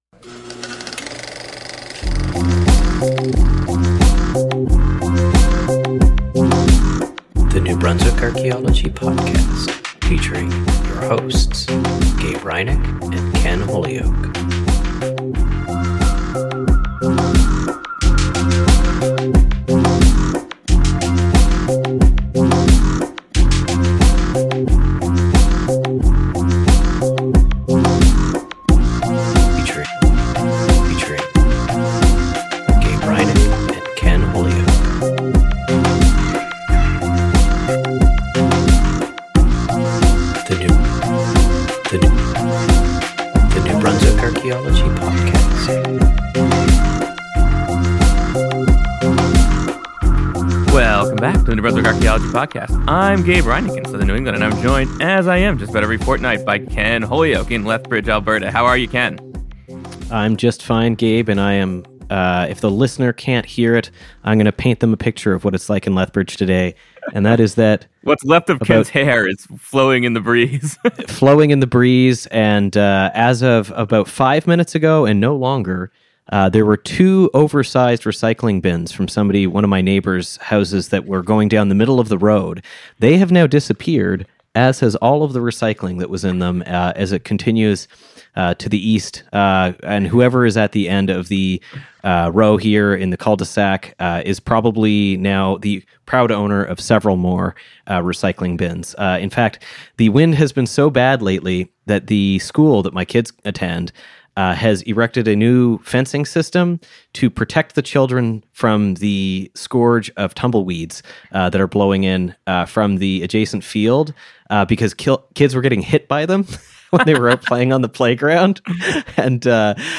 This fortnight we’re traveling with the Northeastern Archaeological Survey van to Fort William Henry and Lake George New York for a recap of the 2025 Eastern States Archaeological Federation Meeting held in Lake George, New York in November of 2025.